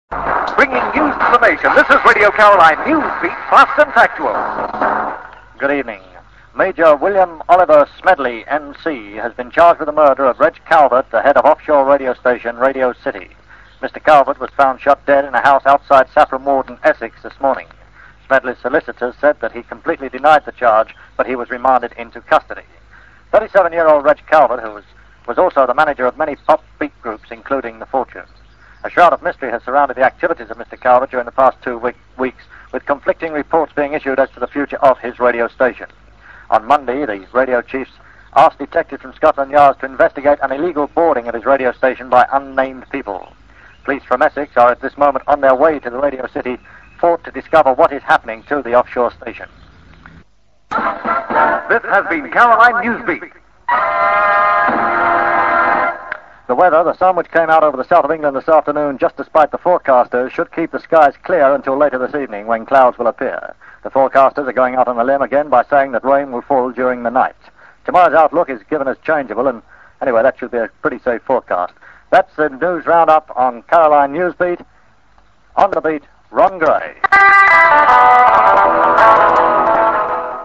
click to hear audio Radio Caroline South reporting the arrest of Major Smedley following Reg Calvert's shooting (duration 1 minute 20 seconds)
calvnews.mp3